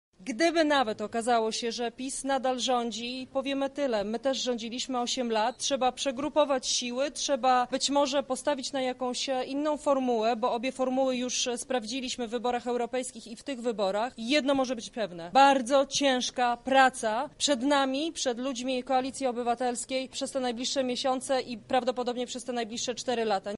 -mówi posłanka Joanna Mucha